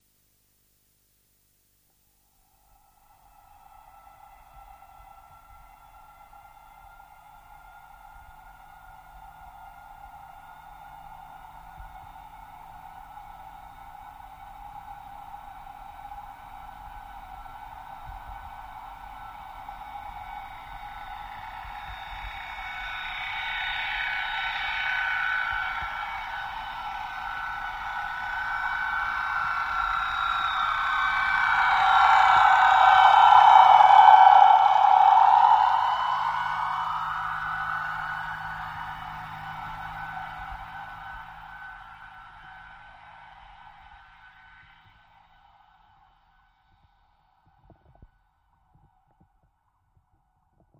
Torpedos
Torpedo Jet By